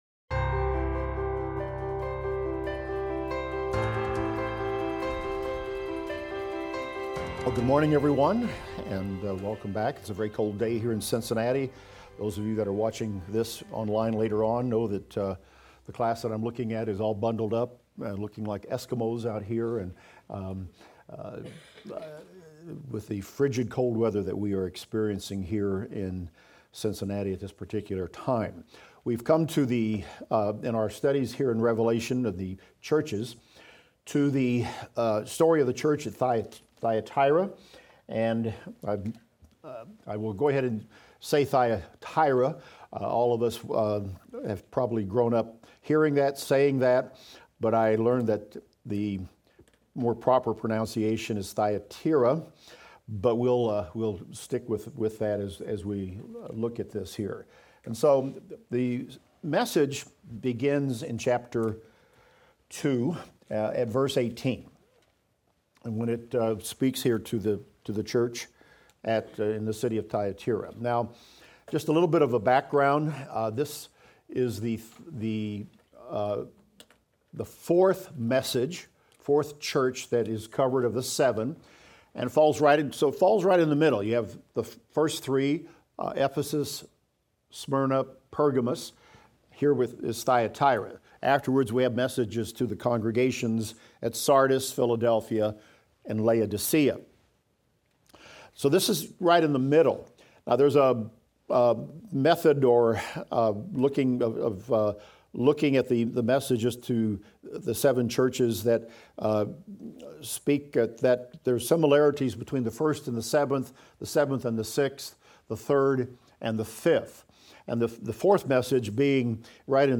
Daniel - Lecture 31 - audio.mp3